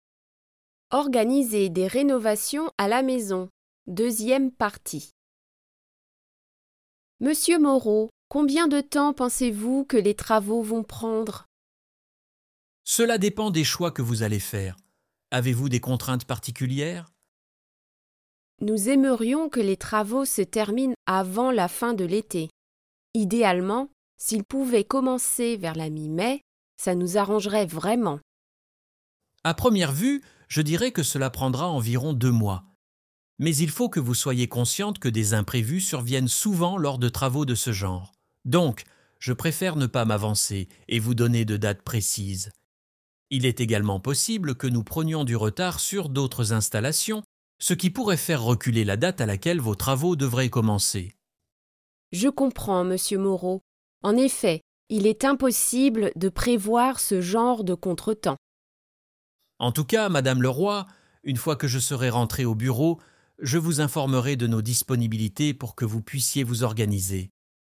Madame Leroy discusses her plans for home renovations with her prospective contractor Monsieur Moreau in this rich dialogue full of great French construction vocabulary and expressions.